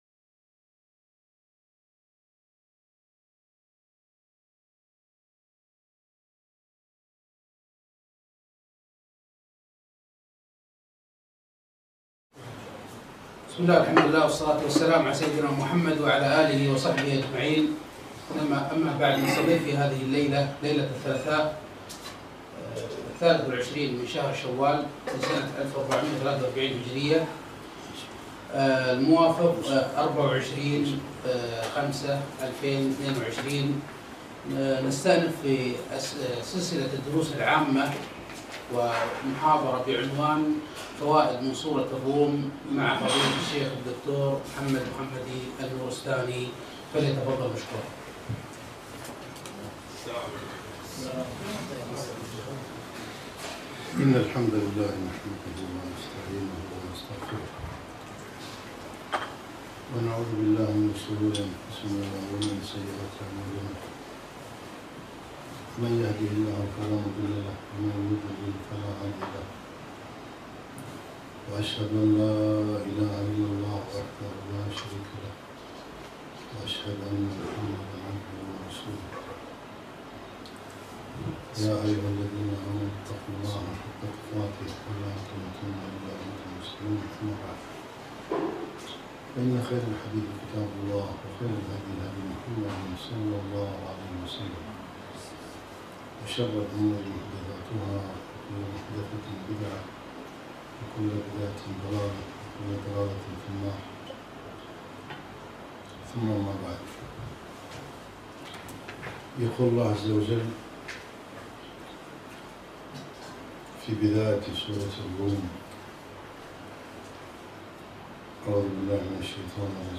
محاضرة - فوائد من سورة الروم